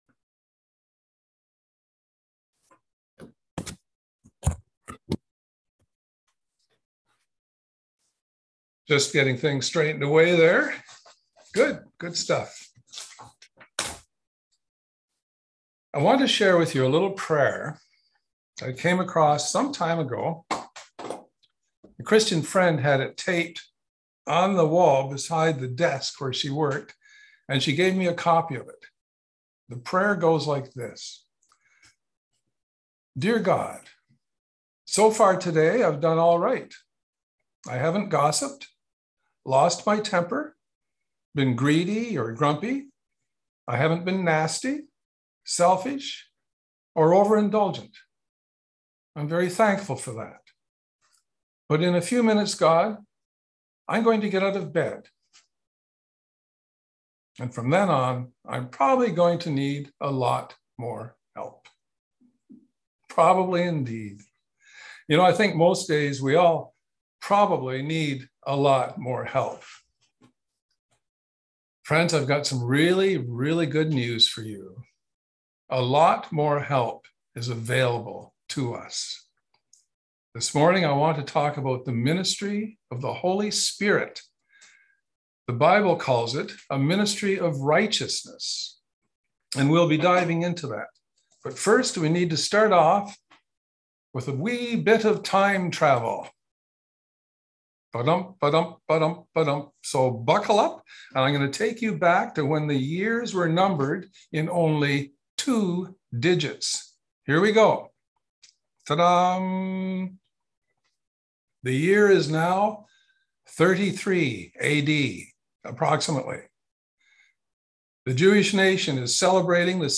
july+24+22+sermon+-+audio.m4a